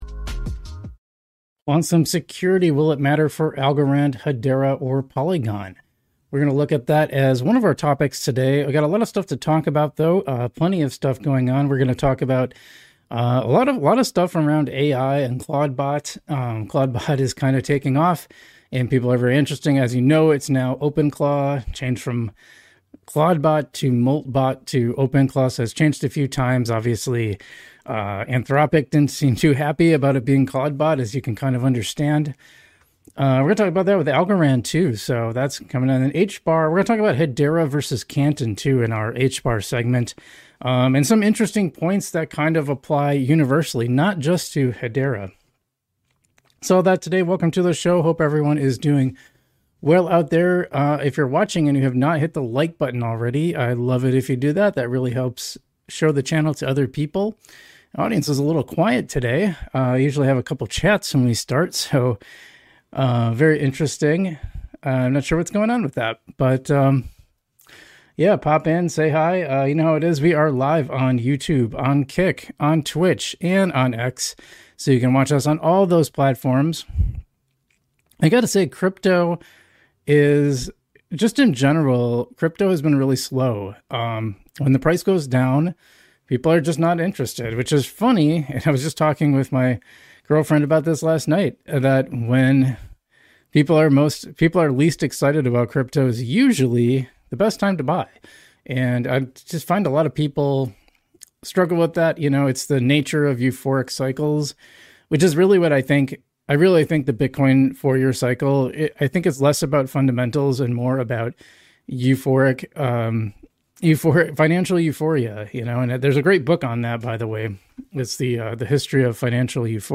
We talk about the quantum security issue in crypto and how important it really is - plus the latest Algorand and Hedera news. We were going to do polygon news too but accidentally muted the mic!